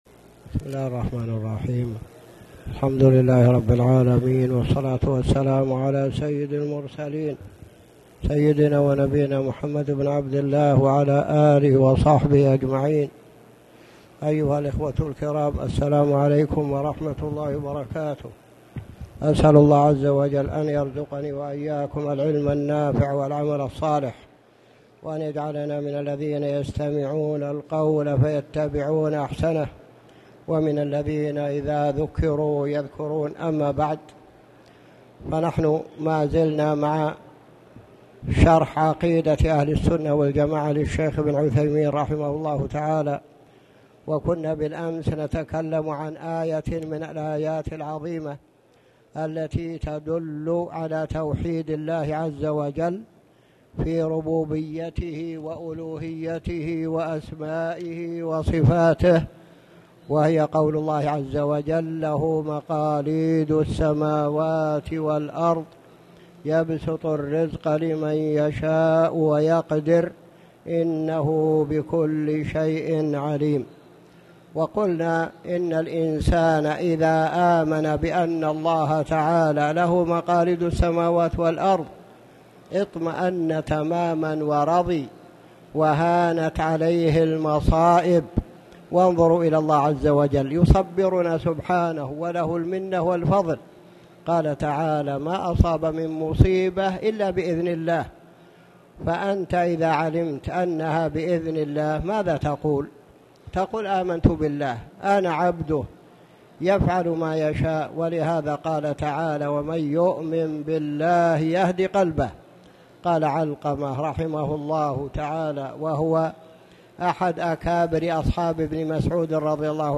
تاريخ النشر ٢٠ شعبان ١٤٣٨ هـ المكان: المسجد الحرام الشيخ